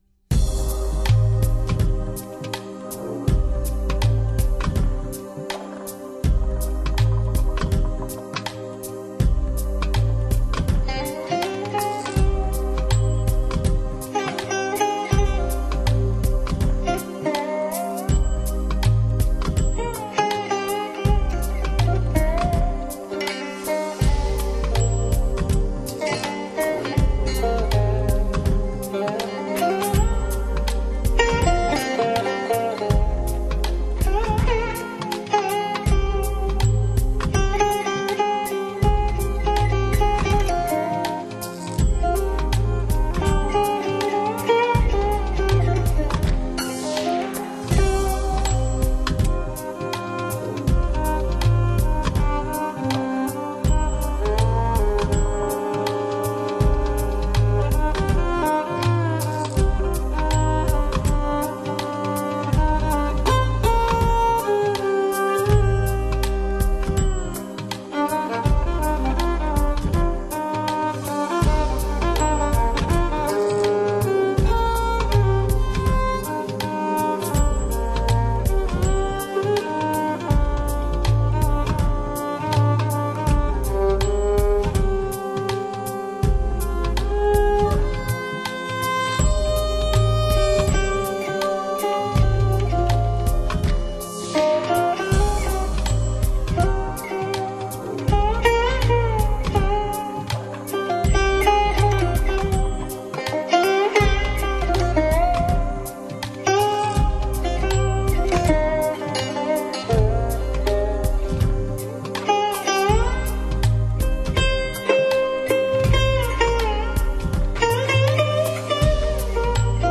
但是，这里挑选出来的曲子都是听来特别的舒服。
新世纪精神元素